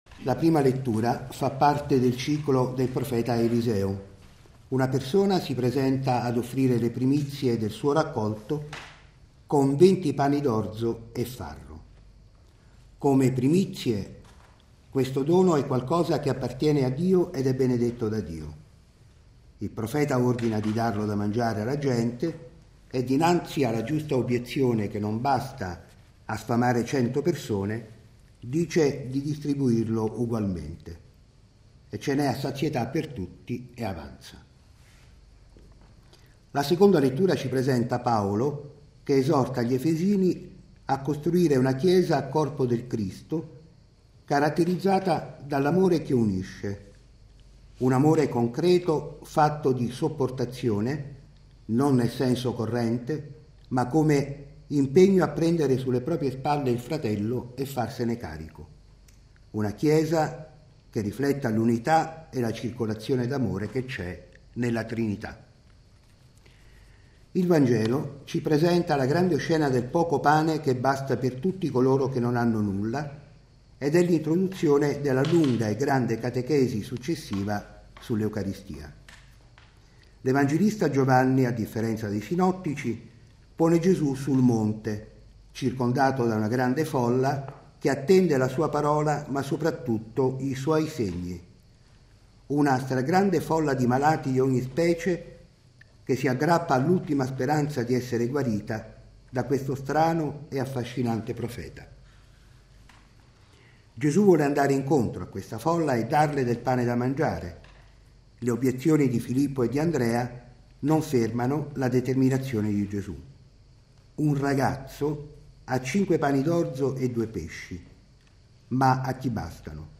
Santa messa in collegamento RAI-Radio1
Assemblea: giovani dell'Oratorio di San Filippo Neri di Roma
Ogni Domenica alle 9.30 dalla Cappella Leone XIII all'interno dei Giardini Vaticani, viene trasmessa la Santa Messa secondo le intenzioni del Sommo Pontefice Benedetto XVI.